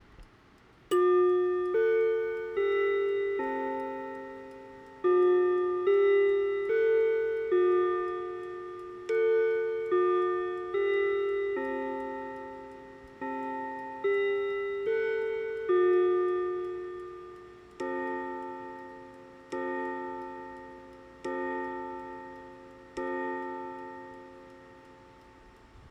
Эта модель оснащена кварцевым механизмом с вестминстерским ходом 4/4. 33 x 25 x 15 cm
Мелодии